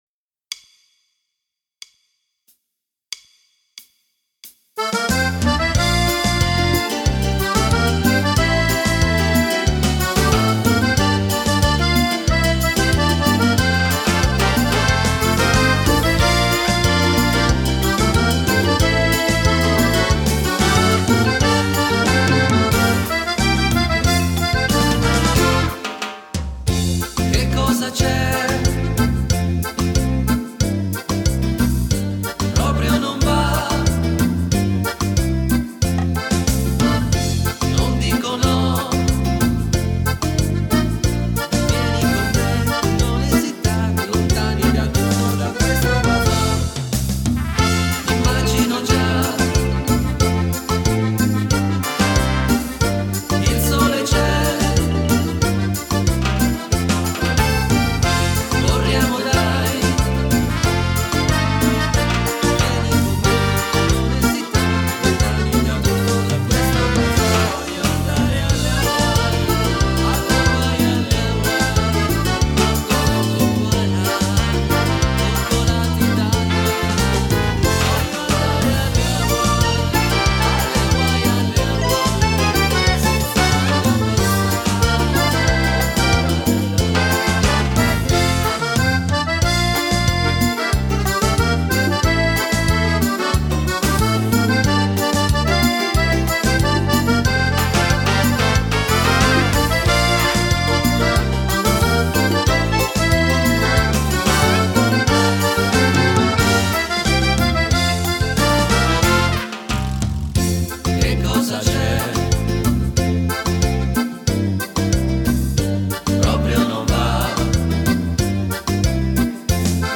Bajon
Donna